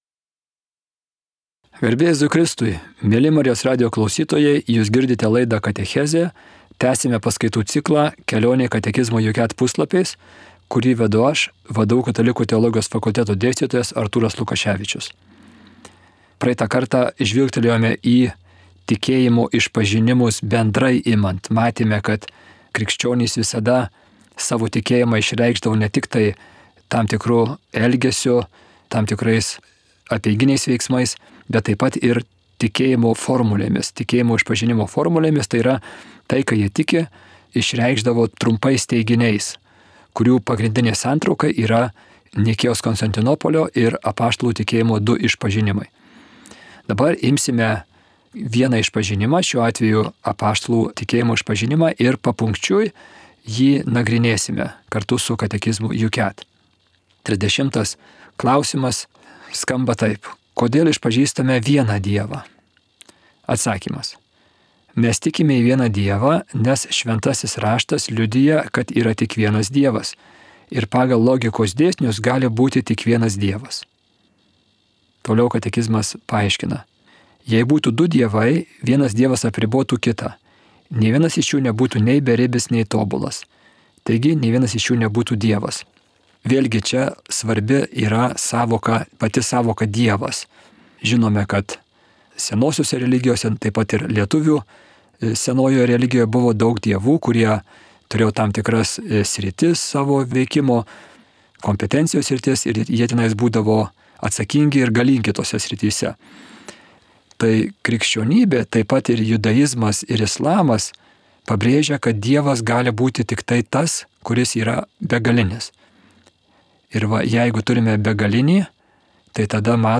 1 Katechezė